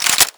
Index of /server/sound/weapons/brightmp5